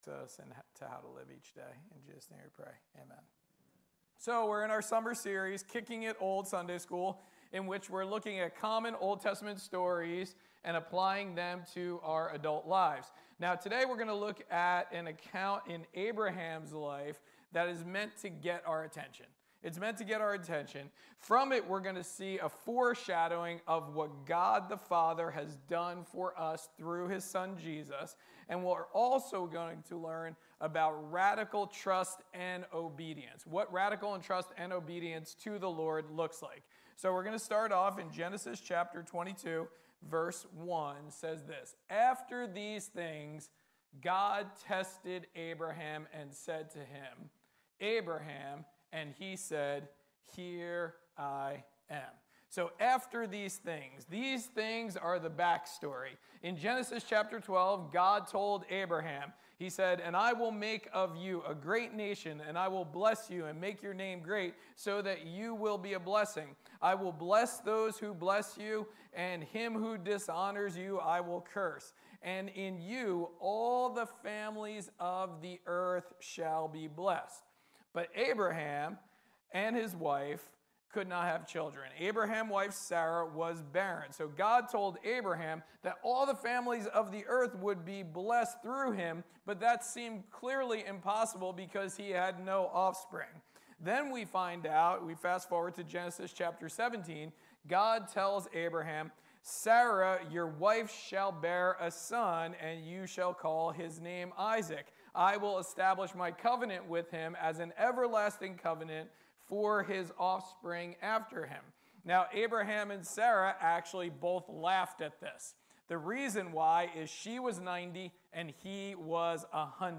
Sermons | Forked River Baptist Church